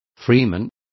Complete with pronunciation of the translation of freeman.